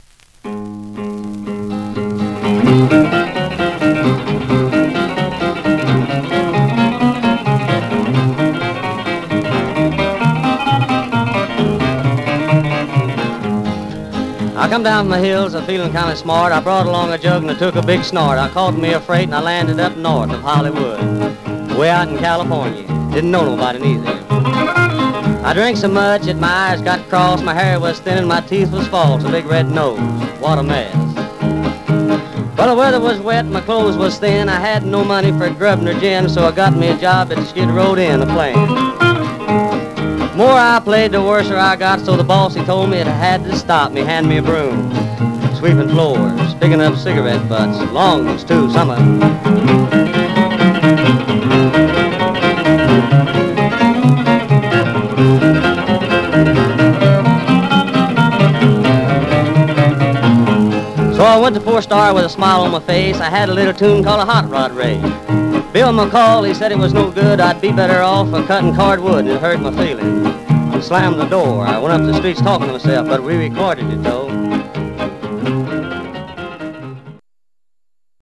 Some surface noise/wear
Mono
Country